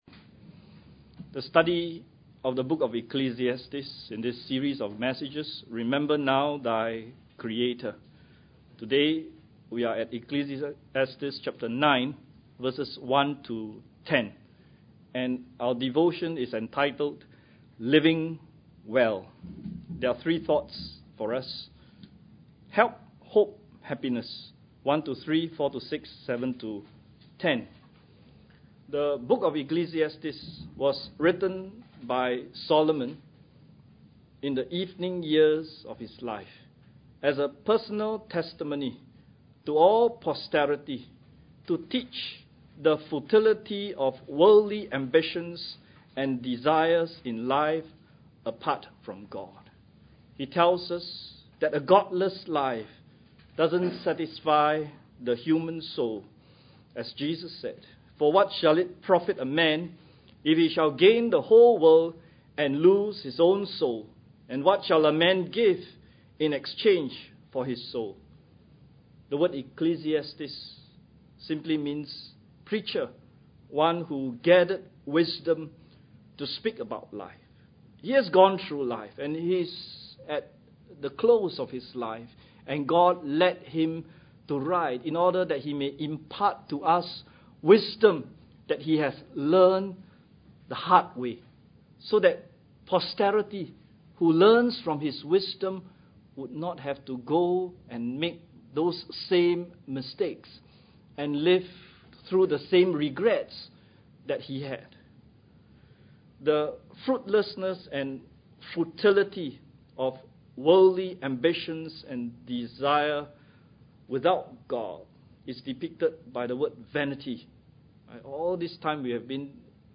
Hymns: RHC 43 God Is Still On the Throne 256 The Bible Stands 243 The Comforter Has Come